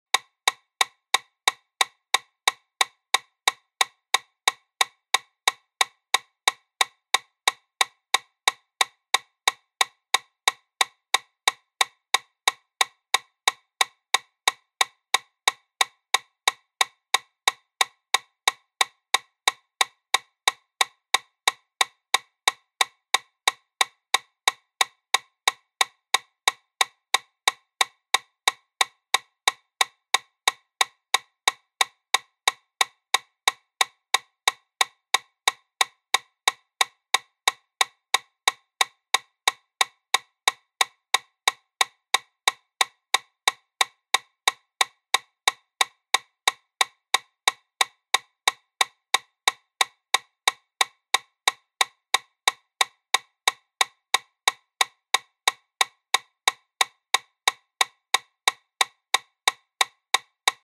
Звуки метронома
180 ударов в минуту